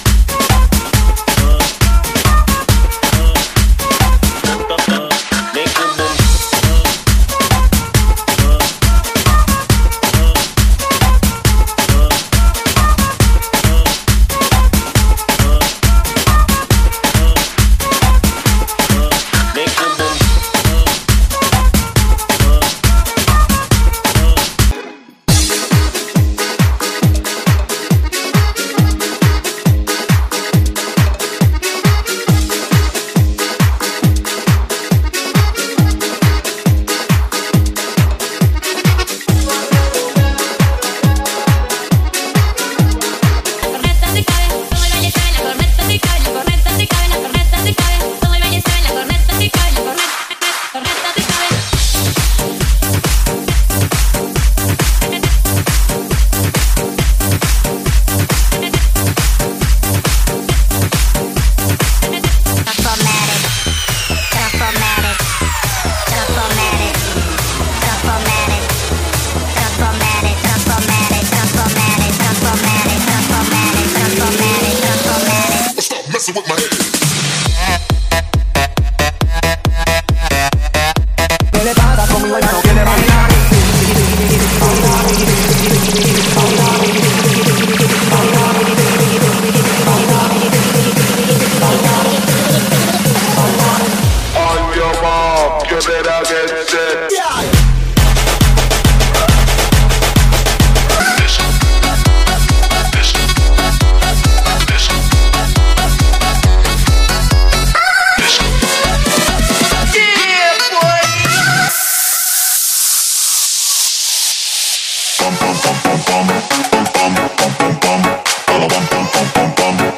GENERO: ELECTRO, REMIX